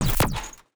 UIClick_Menu Double Hit Rumble Tail 05.wav